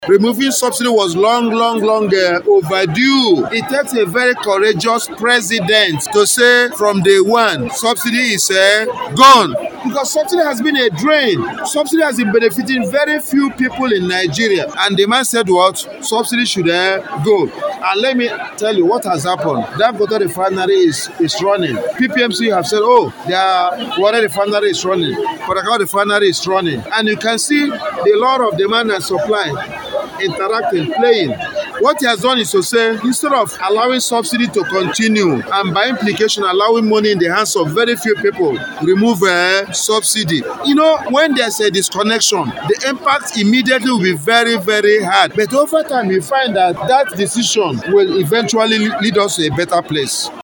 Hon. Nkwonta who made this known during an Agenda Setting Meeting of APC members in Abia South Senatorial Zone comprising of Aba North, Aba South, Ugwunagbo, Ukwa East, Ukwa West and Obingwa, which held at his country home Akwete, maintained that APC in Abia South must galvanize to win elections across board in 2027 stressing that this can only be achieved through membership drive and party dominance as he called on them to bring in more committed members into the party.